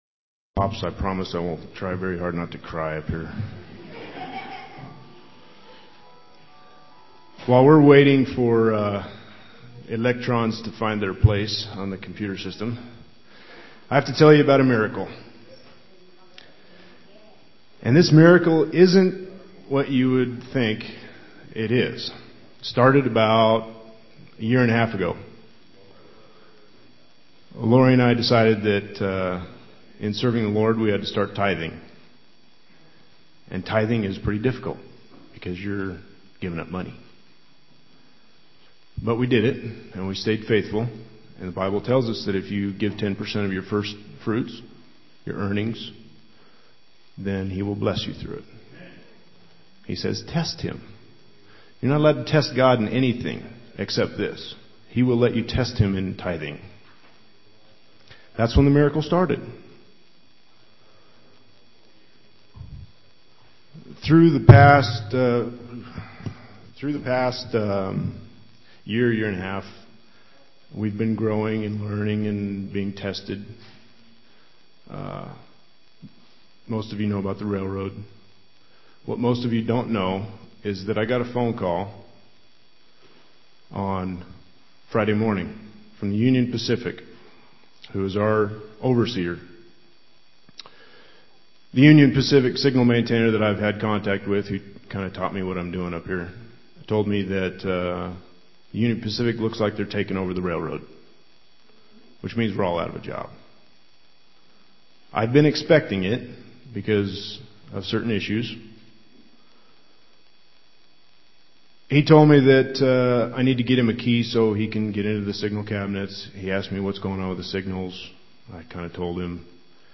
Testimony
2009 Due to technical difficulties, most of the morning service was not recorded.